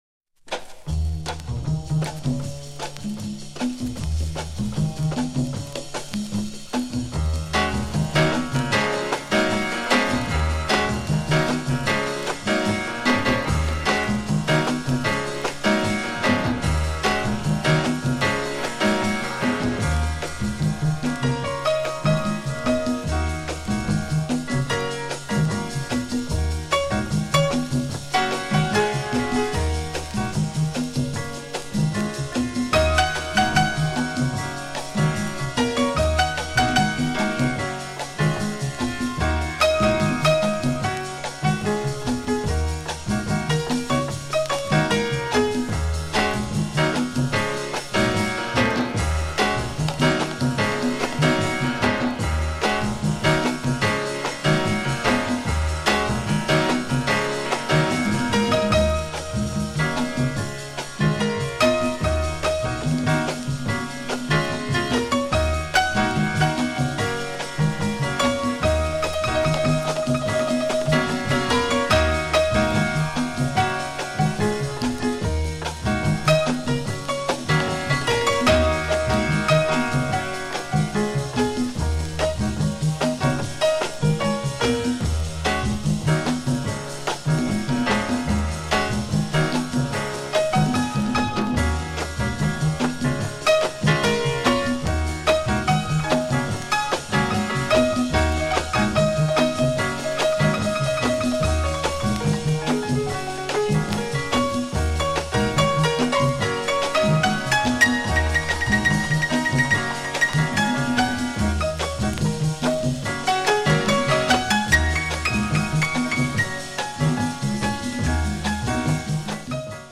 心地良い炸裂感がある「